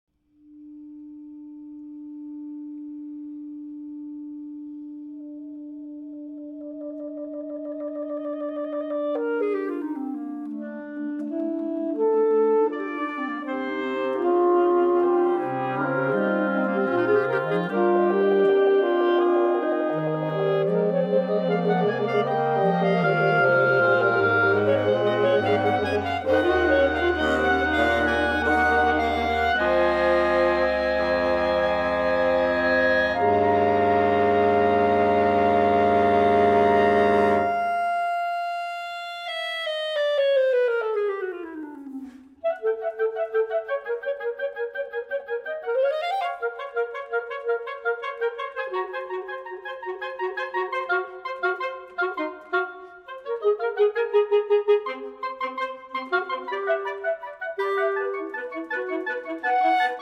Reed Quintet